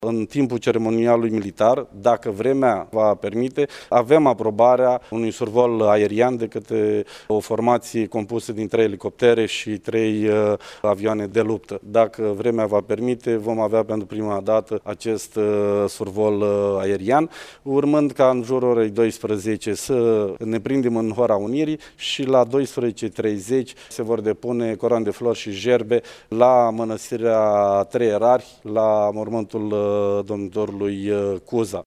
Dacă vremea o va permite, la parada militară de la Iaşi vor participa şi elicoptere şi avioane militare, a adăugat prefectul Marian Şerbescu: